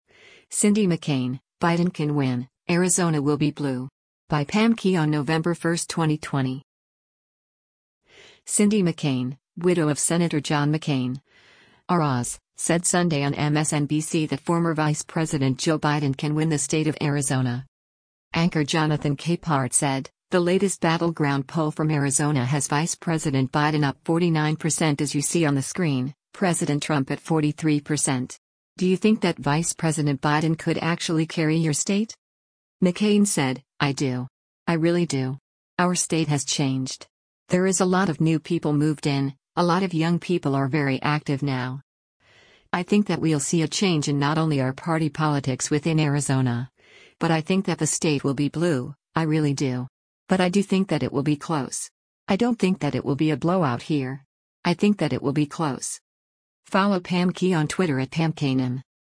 Cindy McCain, widow of Sen. John McCain (R-AZ), said Sunday on MSNBC that former Vice President Joe Biden can win the state of Arizona.